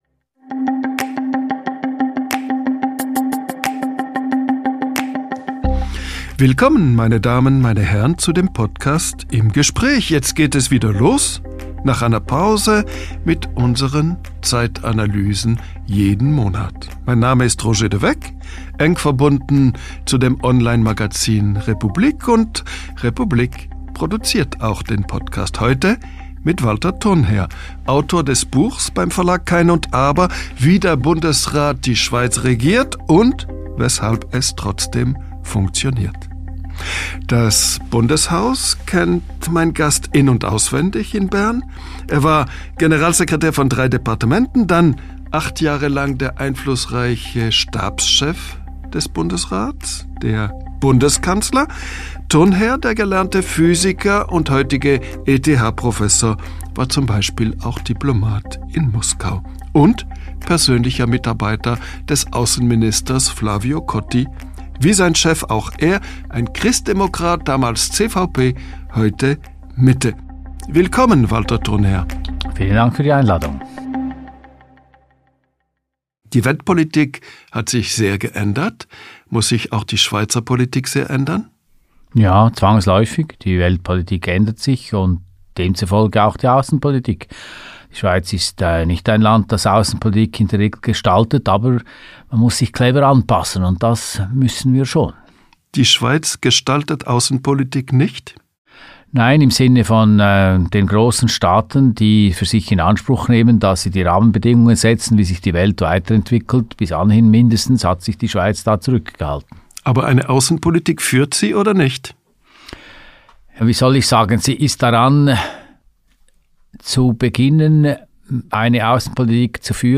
Die Spielregeln der Weltpolitik verändern sich fundamental. Was das für die Schweiz und ihre Aussenpolitik bedeutet: Publizist Roger de Weck im Gespräch mit Alt-Bundeskanzler Walter Thurnherr.